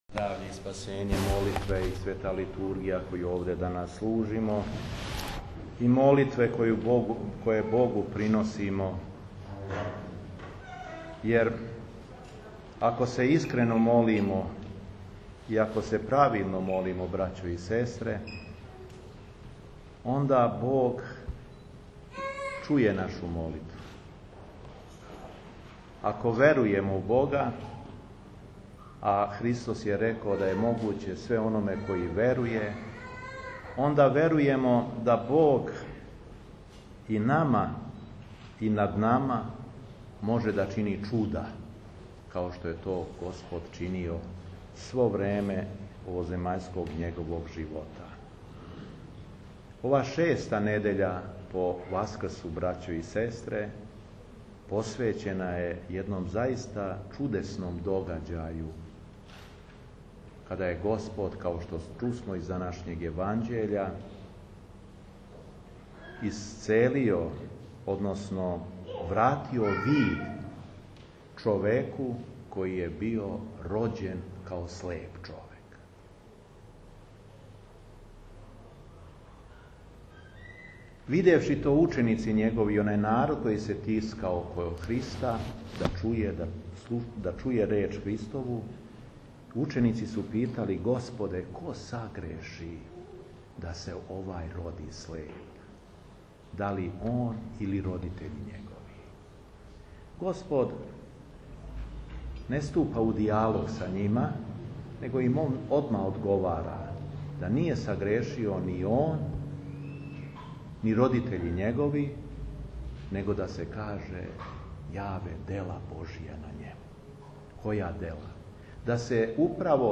У шесту недељу по Васкрсу – недељу слепог, 05. јуна 2016. године, када наша Света Црква прославља преподобног Михаила Исповедника, Његово Преосвештенство Епископ шумадијски Г. Јован служио је Свету Архијерејску Литургију у храму Вазнесења Господњег у Араповцу.
Беседа Епископа шумадијског Г. Јована